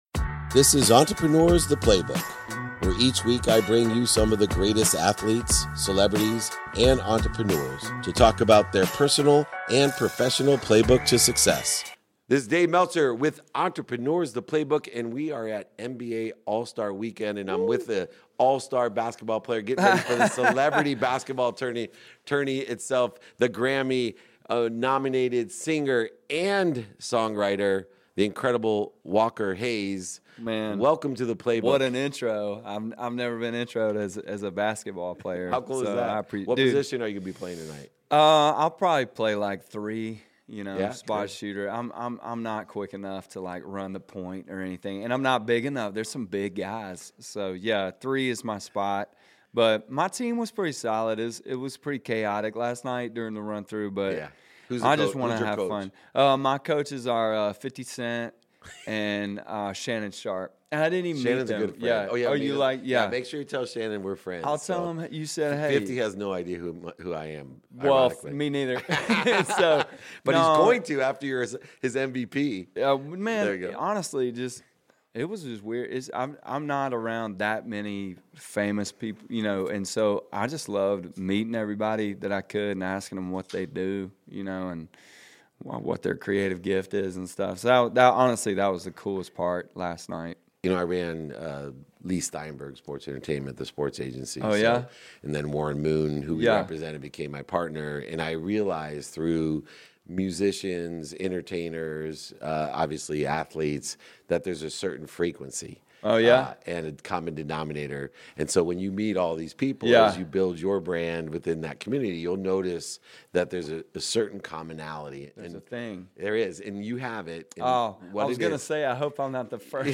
In today's episode, I sit down with the multi-talented Walker Hayes during NBA All-Star Weekend.